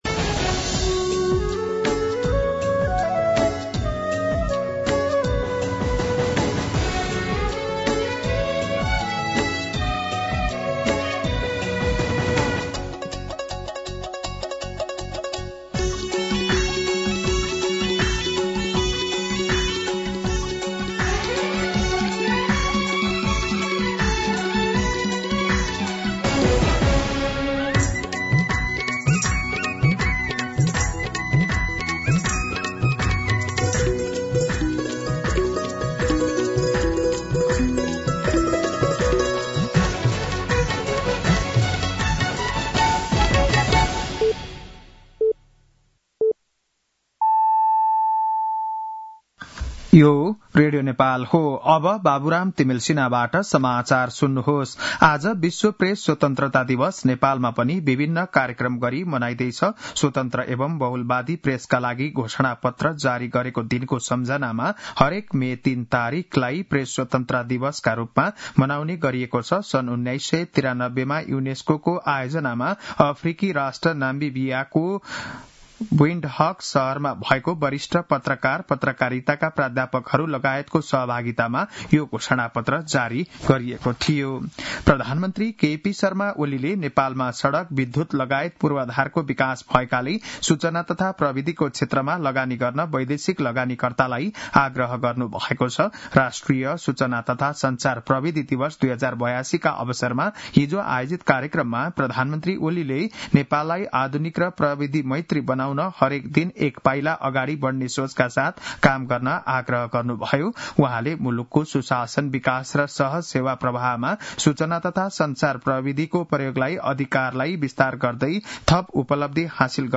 बिहान ११ बजेको नेपाली समाचार : २० वैशाख , २०८२